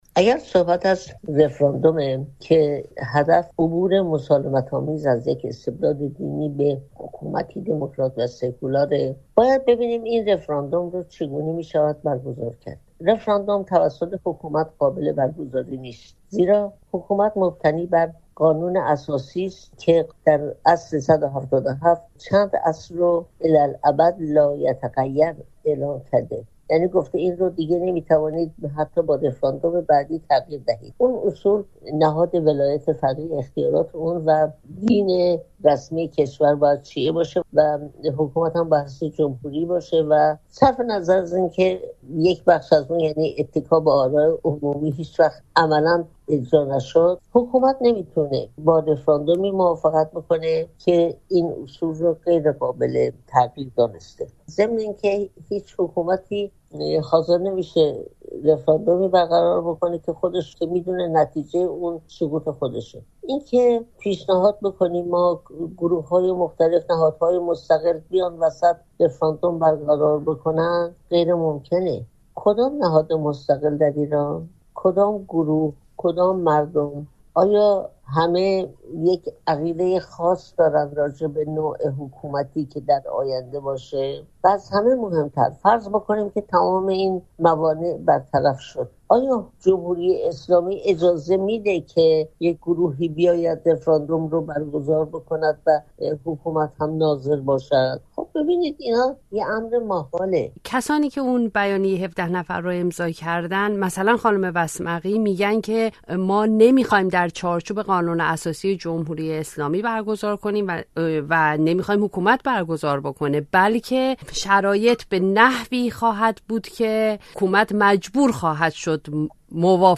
شیرین عبادی،‌ برندهٔ جایزهٔ صلح نوبل و فعال حقوق بشر ساکن لندن که از منتقدان این مسئله است، در گفت‌وگو با رادیوفردا می‌گوید که پیش شرط برگزاری رفراندوم،‌ براندازی حکومت است.